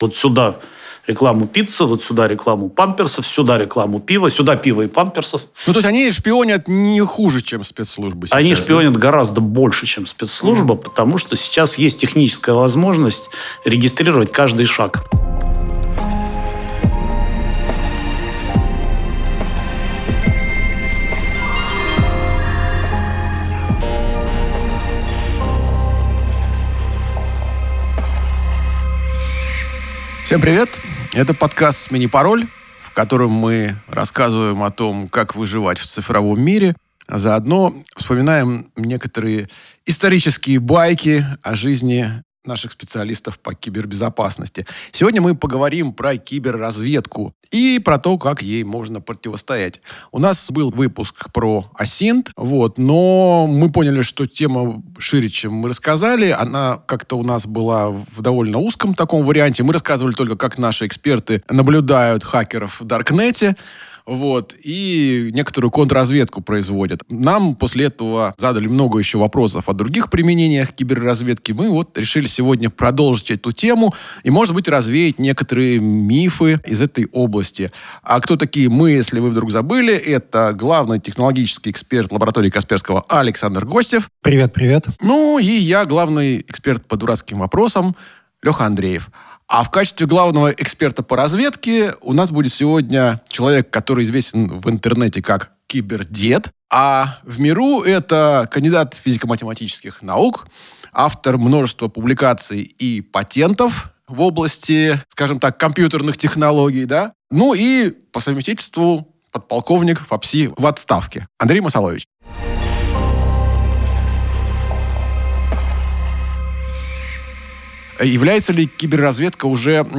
Документально-разговорный подкаст о кибербезопасности.